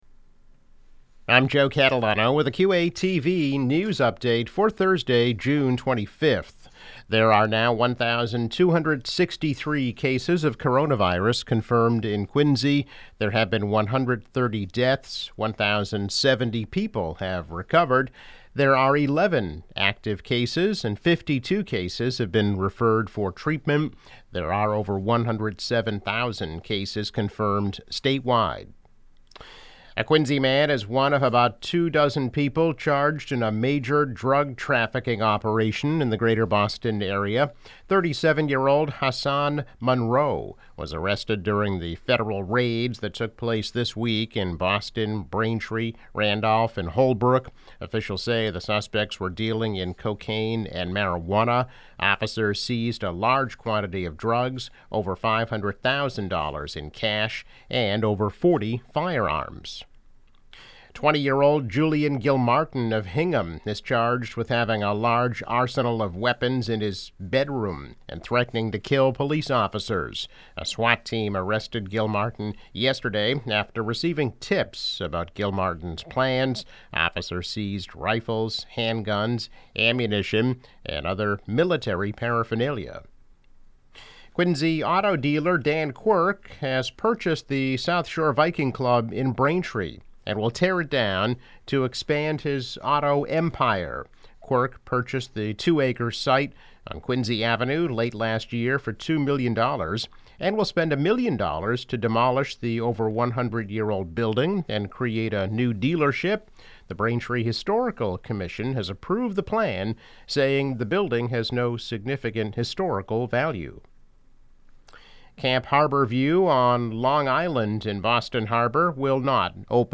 Daily news update.